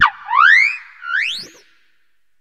Cri de Dofin dans Pokémon HOME.